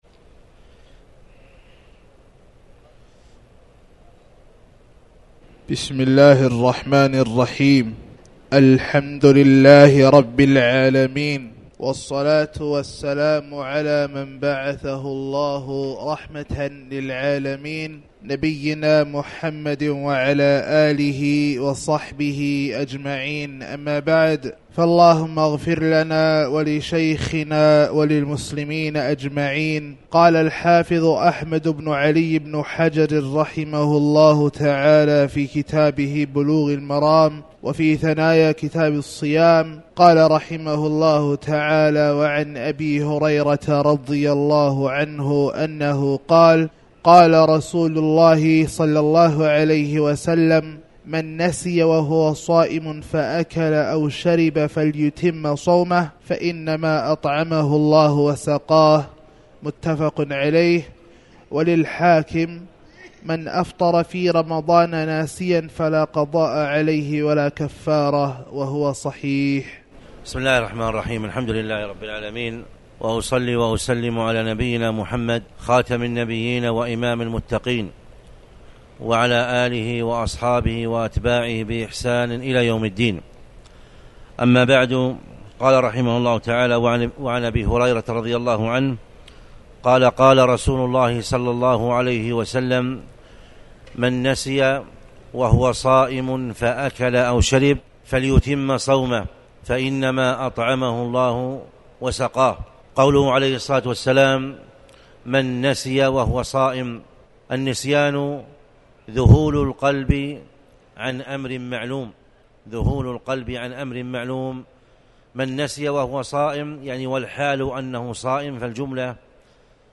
تاريخ النشر ٢٤ شعبان ١٤٣٩ هـ المكان: المسجد الحرام الشيخ